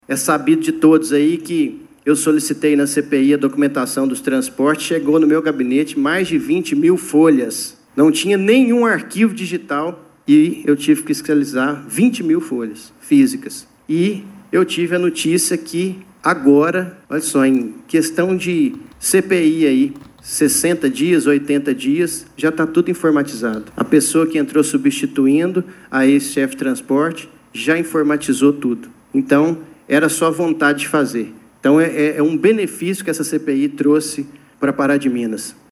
O vereador Leonardo Xavier Assunção Silva (Novo), autor da proposta de abertura da investigação, utilizou seu tempo na tribuna para atualizar os trabalhos da Comissão Parlamentar de Inquérito (CPI), instaurada em 18 de novembro de 2025.